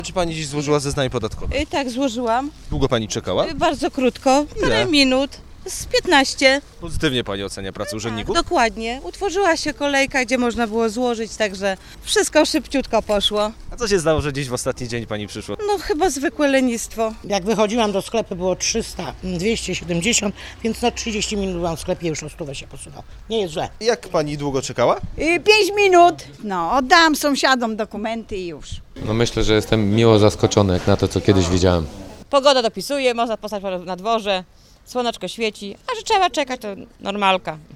Byliśmy dziś przed 11 w Urzędzie Skarbowym w Zielonej Górze. Mimo, że w placówce duży ruch, to mieszkańcy nie narzekali na pracę urzędników: